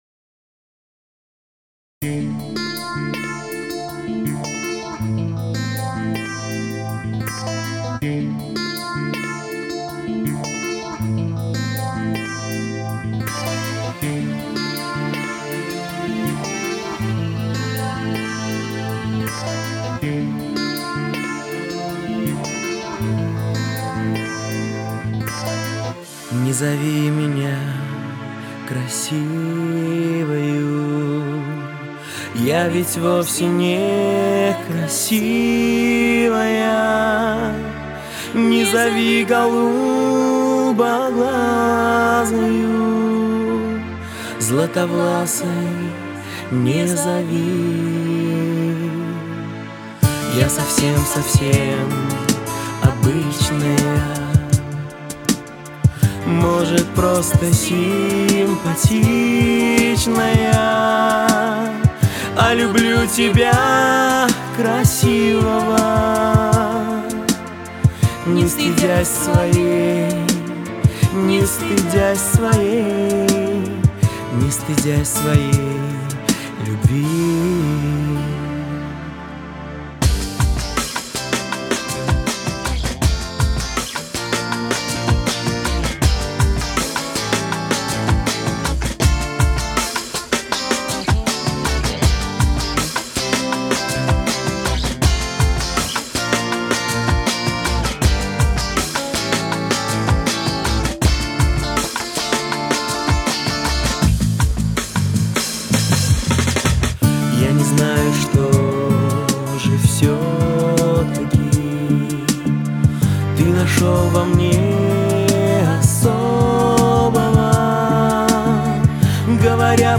Голосок женский...такой проникновенный:)